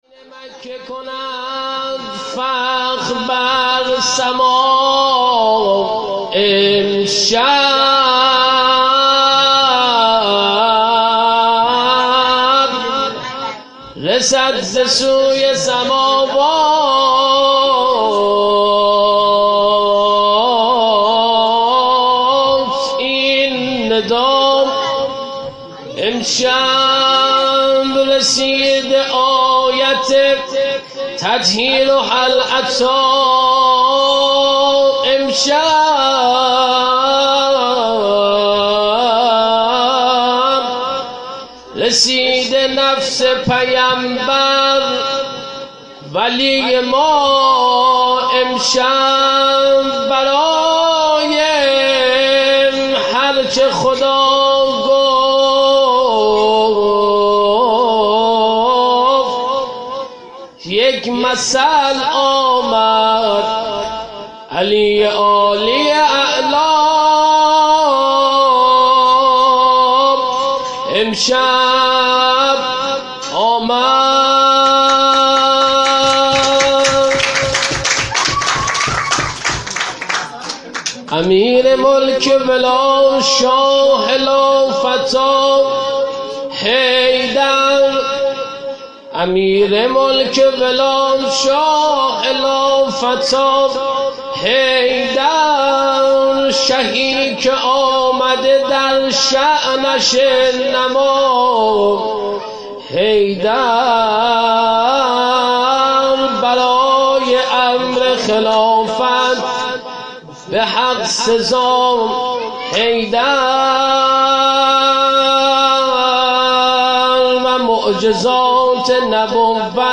هیأت زوارالزهرا سلام اللّه علیها